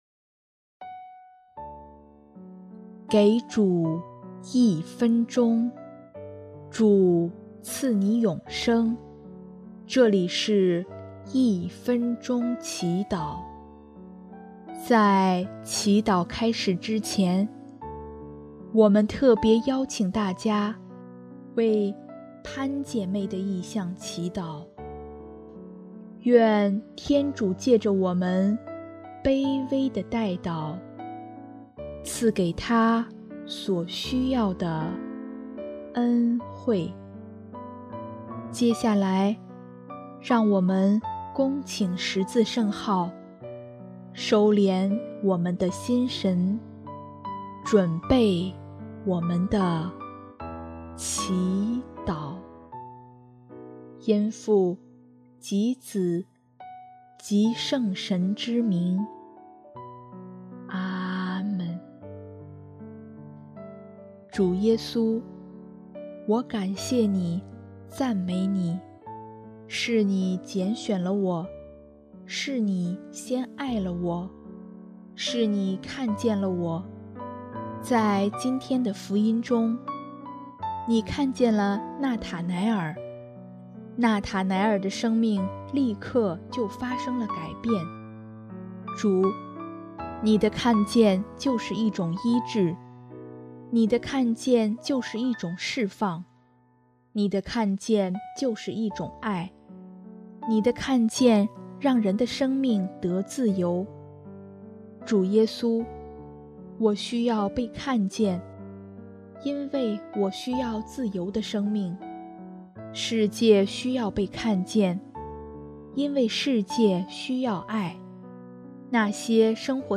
【一分钟祈祷】|8月24日 需要被看见